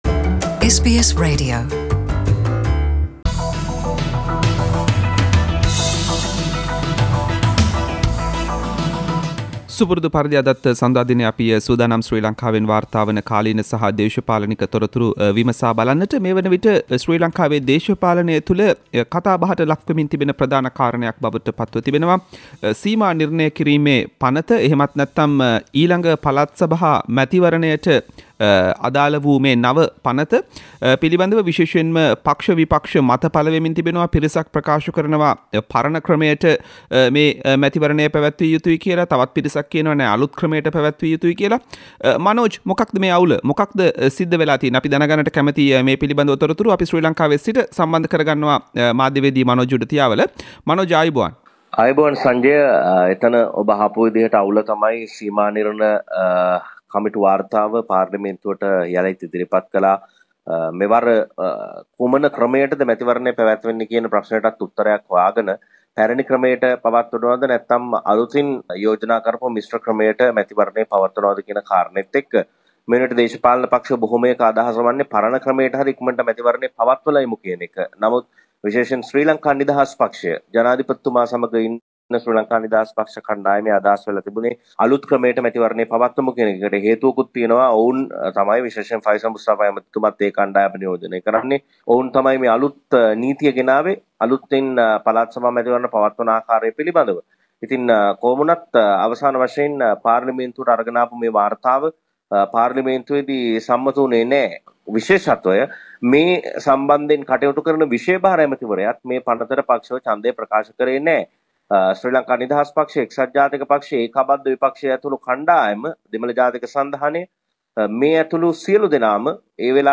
සිමා නිර්ණ වාර්තාව පාර්ලිමේන්තුවේදී පරාජය වෙයි. විෂය භාර අමාත්‍යවරයාත් විරුද්ධව ජන්දය දෙයි - SBS සිංහල සතියේ දේශපාලනික විත්ති විමසුම
ශ්‍රී ලංකාවේ සිට වාර්තා කරයි